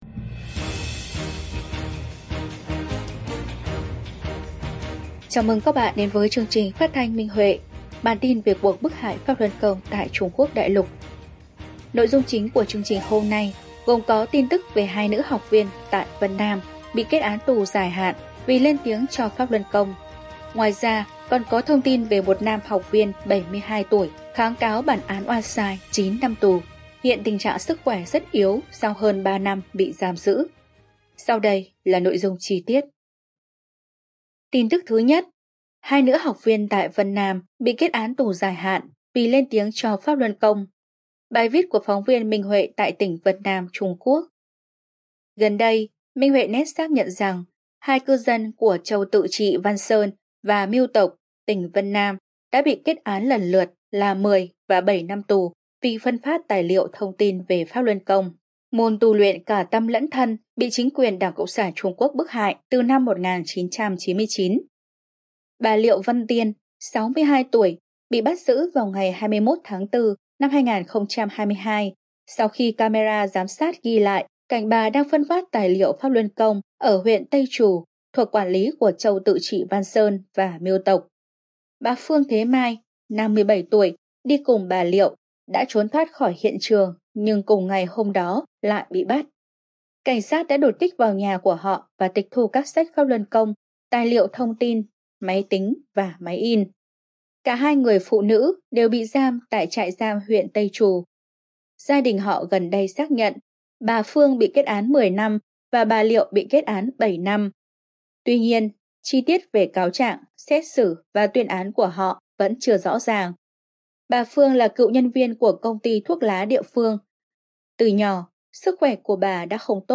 Chương trình phát thanh số 54: Tin tức Pháp Luân Đại Pháp tại Đại Lục – Ngày 28/11/2023